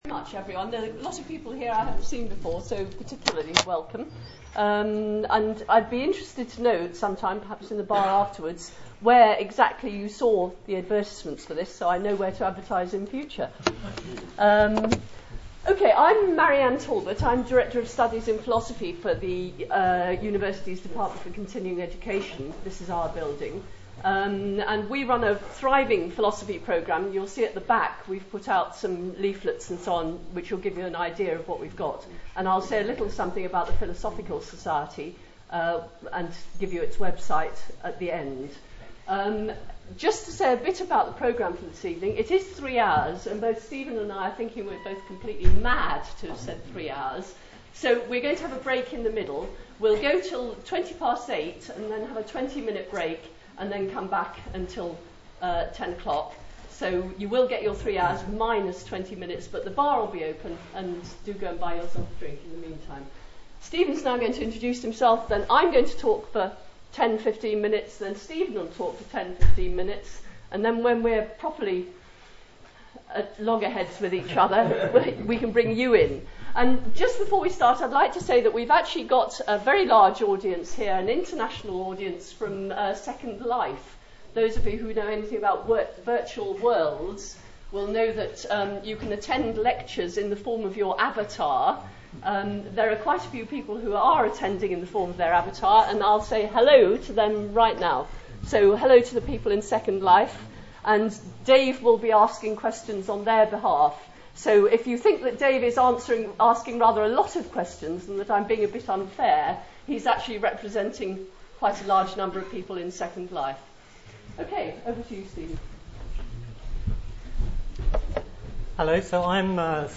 Debate on Dawkins' 'God Delusion'